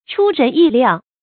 注音：ㄔㄨ ㄖㄣˊ ㄧˋ ㄌㄧㄠˋ